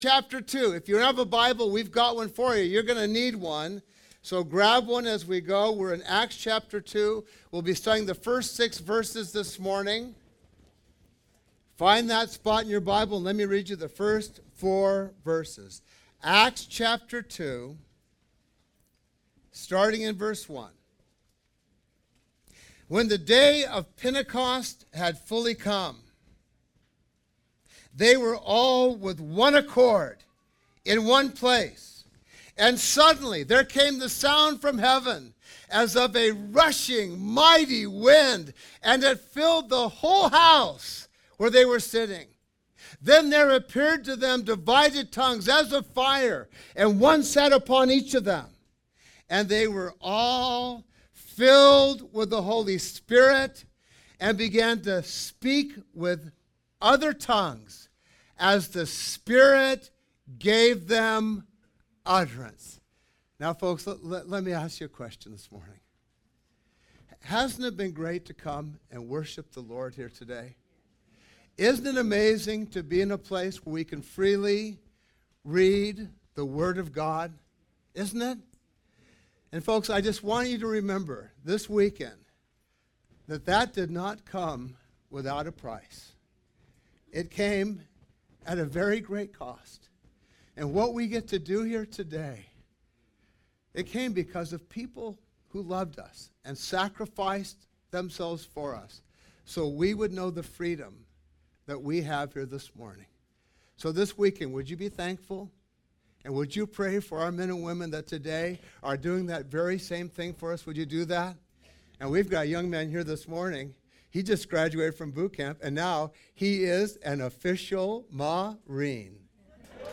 On the day of Pentecost, the early Church receives the Holy Spirit. (Watch this sermon on Youtube)